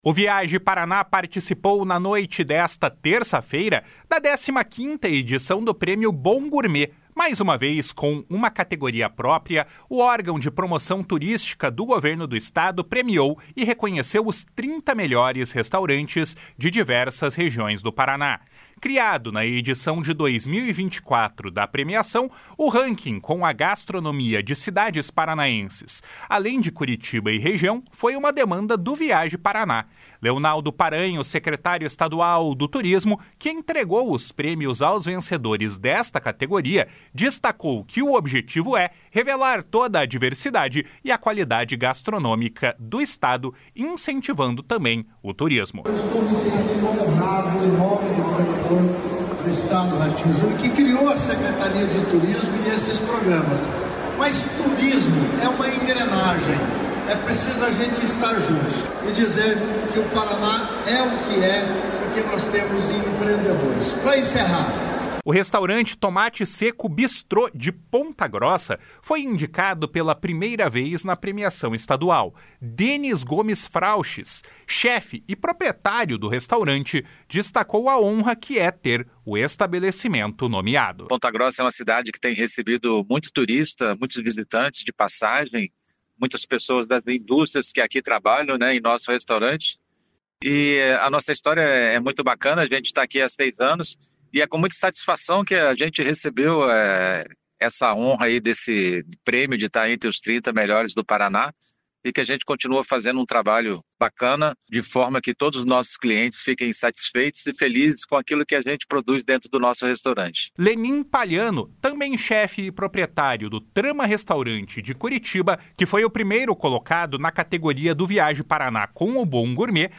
Leonaldo Paranhos, secretário estadual do Turismo, que entregou os prêmios aos vencedores da categoria, destacou que o objetivo é revelar toda a diversidade e a qualidade gastronômica do Estado, incentivando também o turismo. // SONORA LEONALDO PARANHOS //